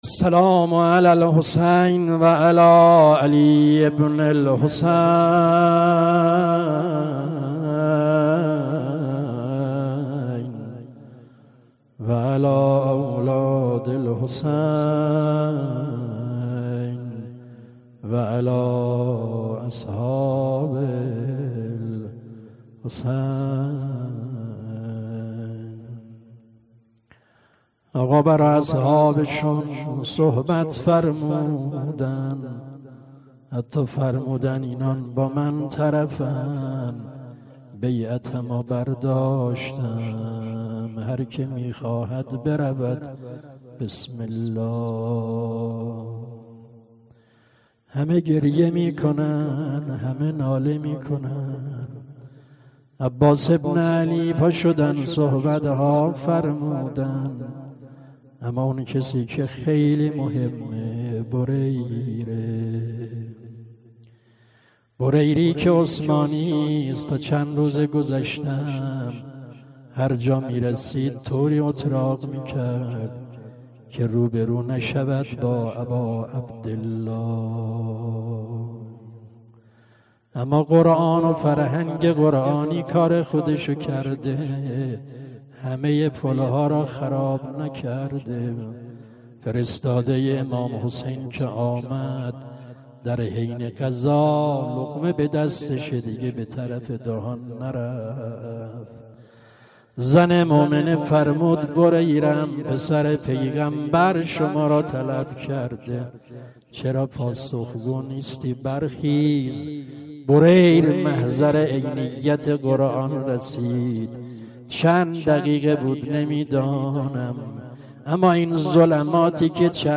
شب عاشورا _ روضه حاج آقا فلسفی.mp3